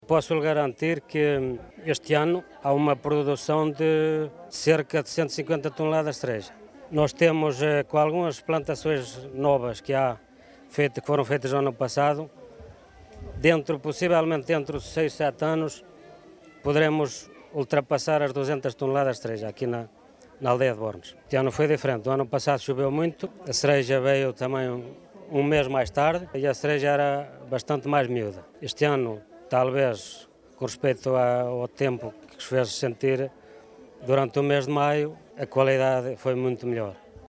Um produção superior à do ano passado mas que se prevê ser ainda maior em menos de oito anos, avança António Romão, presidente da União de Freguesias de Bornes e Burga.